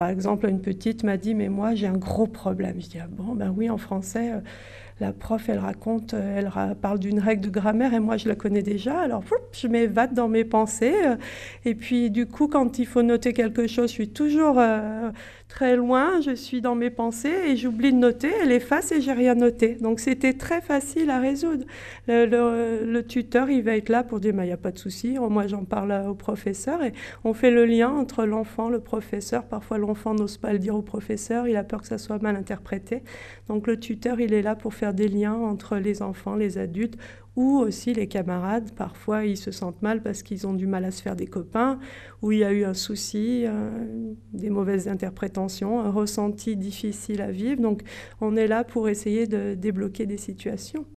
C'était ce matin, sur France Bleu Drôme-Ardèche :)